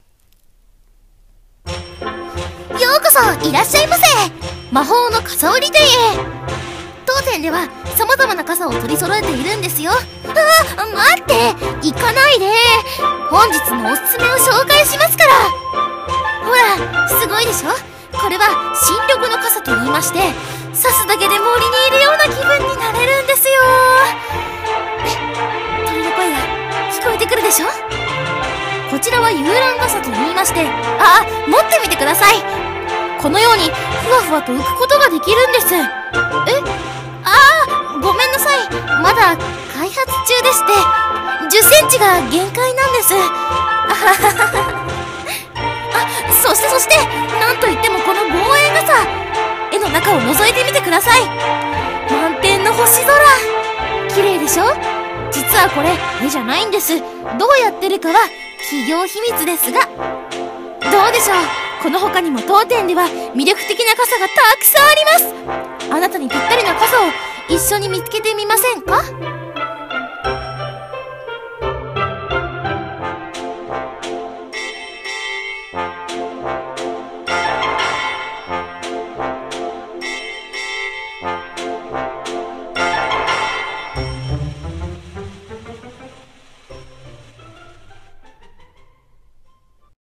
【1人声劇】魔法の傘売り店